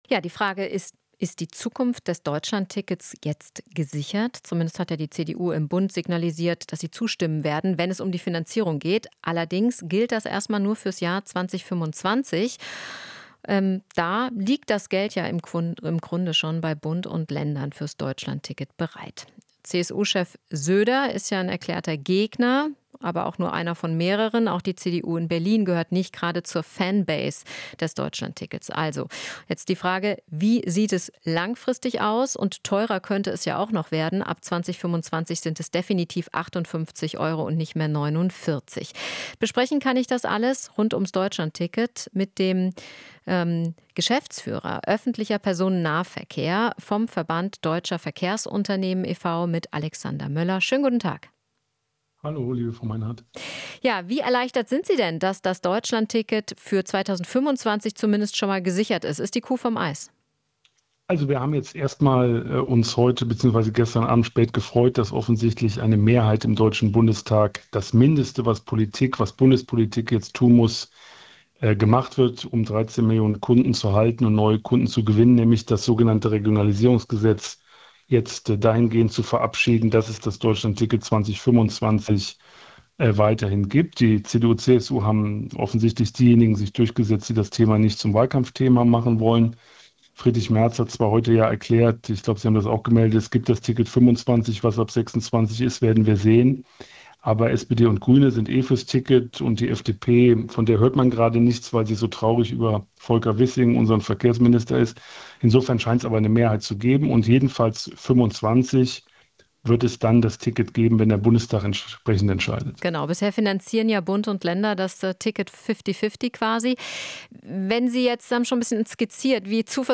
Interview - VDV: Deutschlandticket über 2025 hinaus hängt von Wahl ab